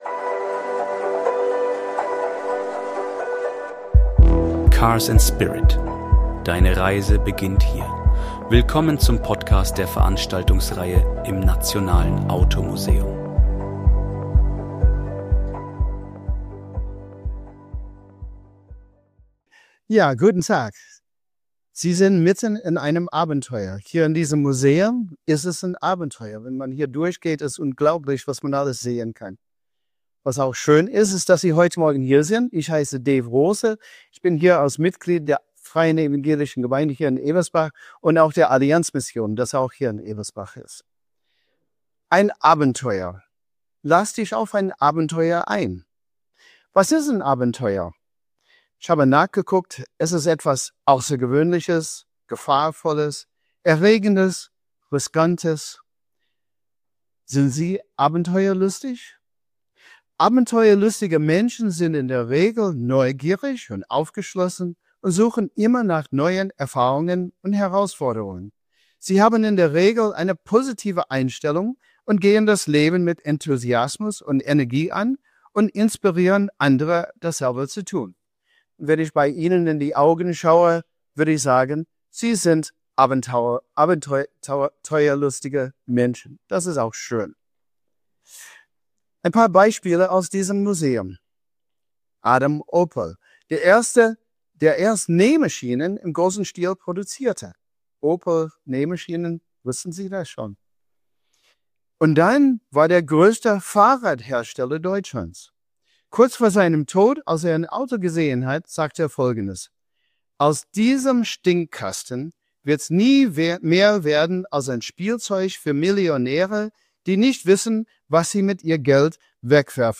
Die Veranstaltungsreihe im Nationalen Automuseum.